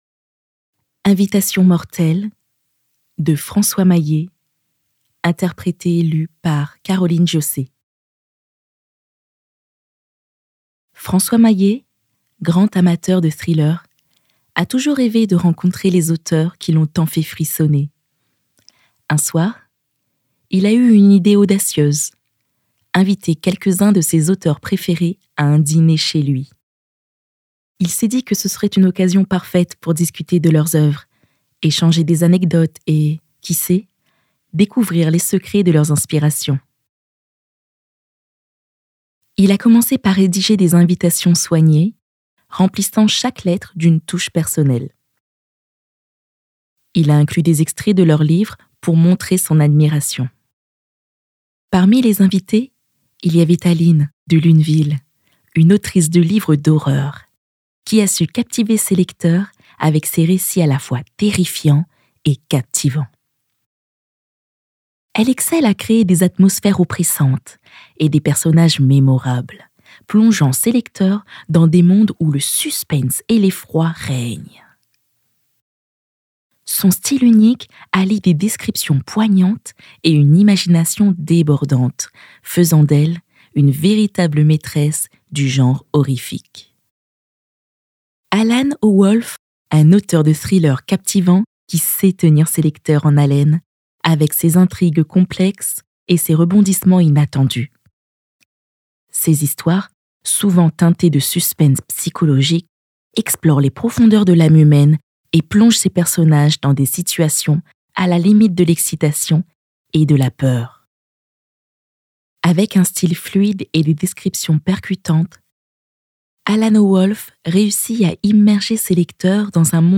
extrait livre audio
- Mezzo-soprano